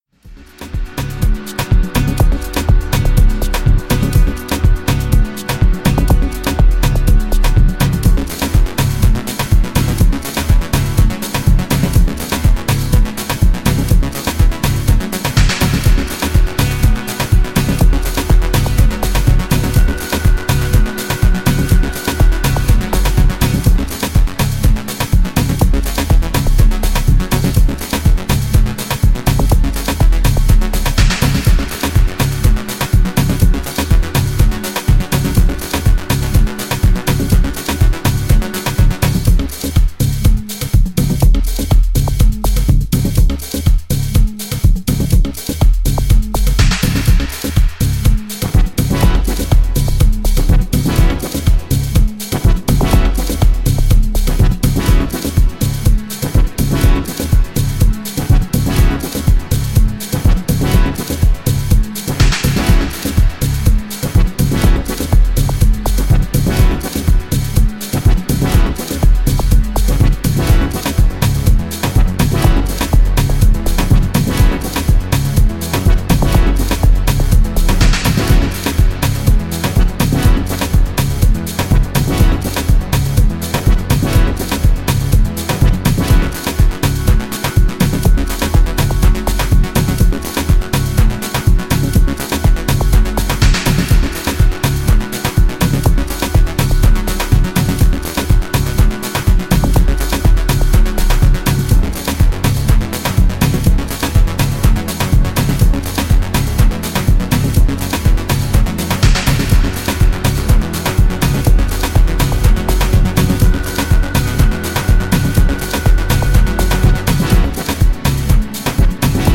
An odyssey of fierce percussions and thunderous basslines
vibrating and hipnotising